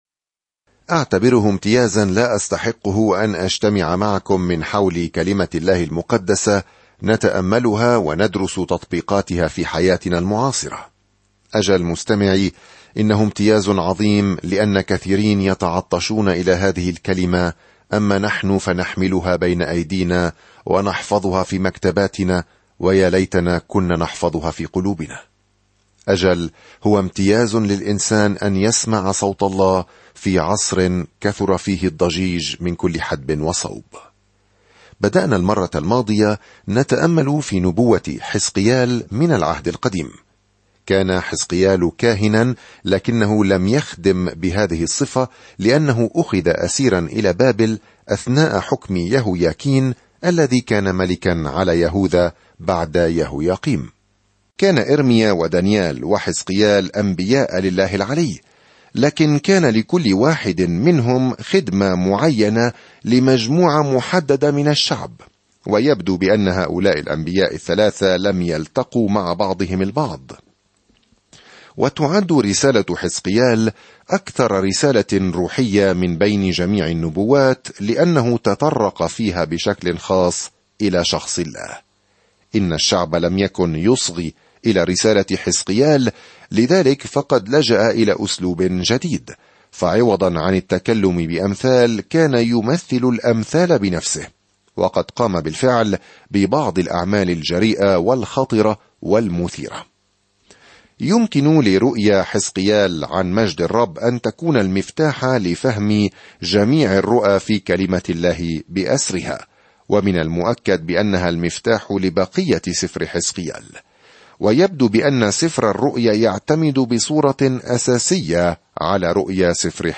الكلمة حِزْقِيَال 5:1-28 يوم 1 ابدأ هذه الخطة يوم 3 عن هذه الخطة لم يستمع الناس إلى كلمات حزقيال التحذيرية للعودة إلى الله، فبدلاً من ذلك مثل الأمثال الرؤيوية، وقد اخترقت قلوب الناس. سافر يوميًا عبر حزقيال وأنت تستمع إلى الدراسة الصوتية وتقرأ آيات مختارة من كلمة الله.